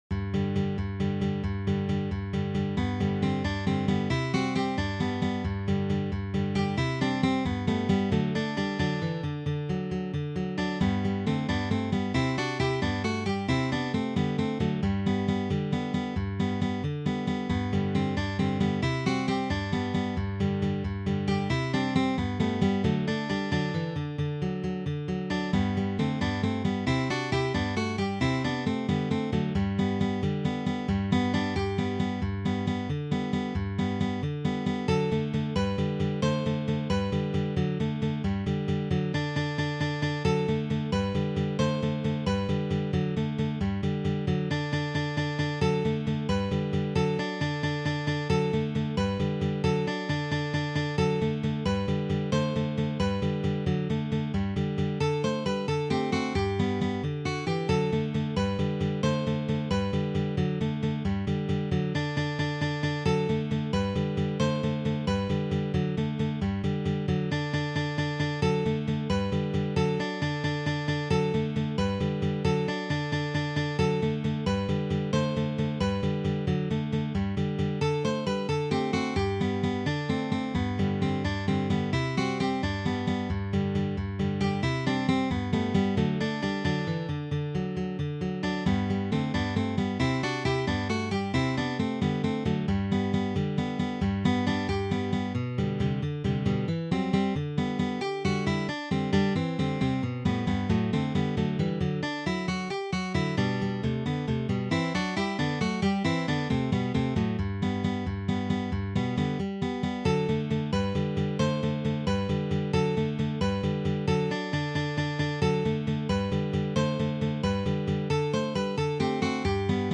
for three guitars
The tempo may be played quite fast.